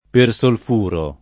persolfuro [ per S olf 2 ro ] s. m. (chim.)